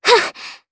Annette-Vox_Casting1_jp.wav